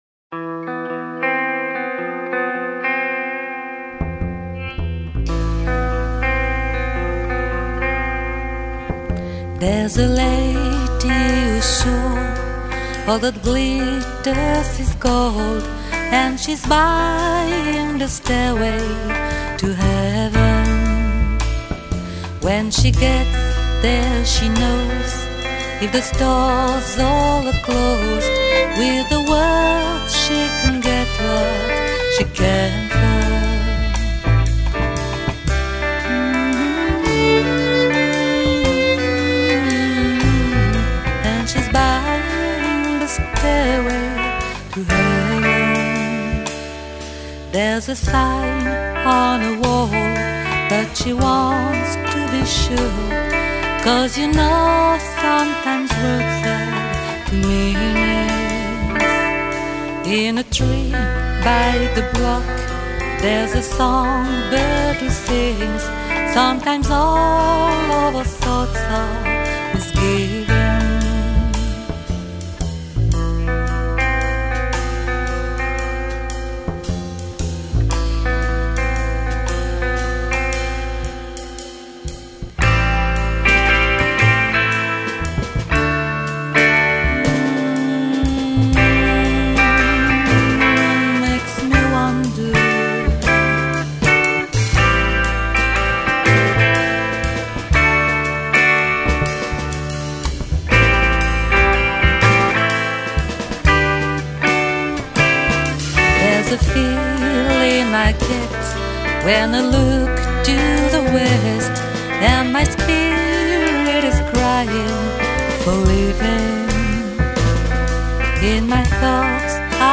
sa voix tour à tour énergique et charmeuse
guitare
batterie